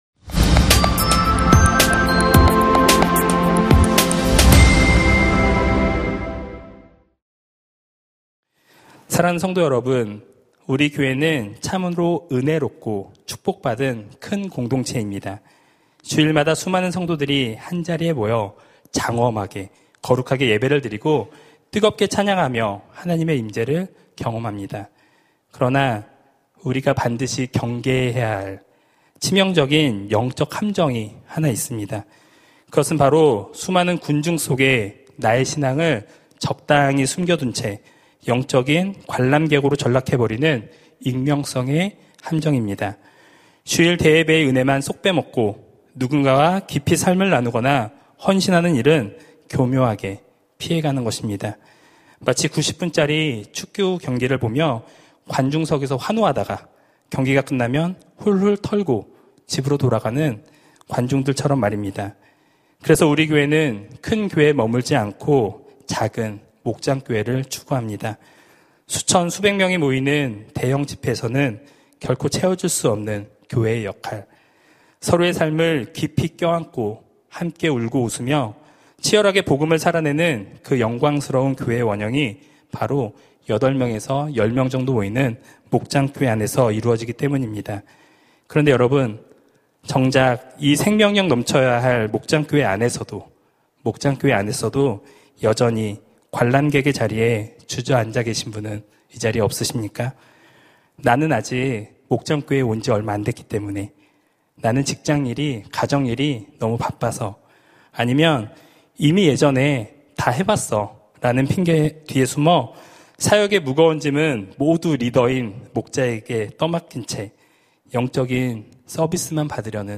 설교 : 수요향수예배